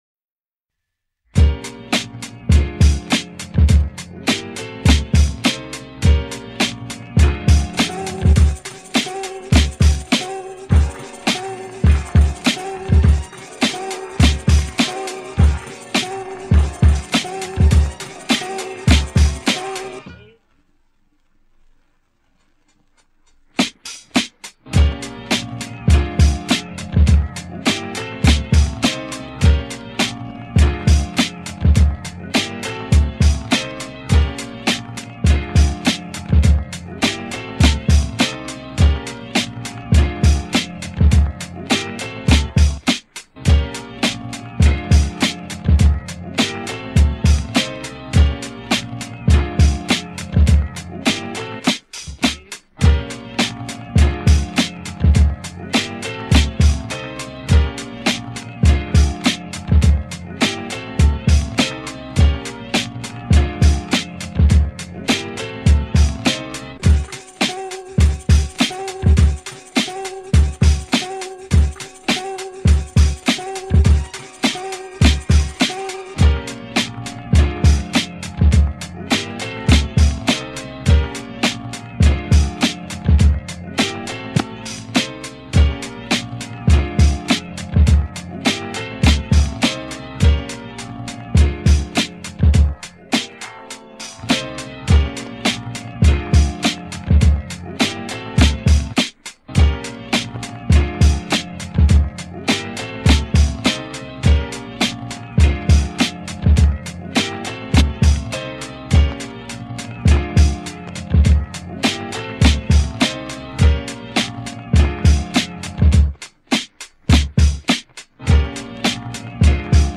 Throwback Instrumental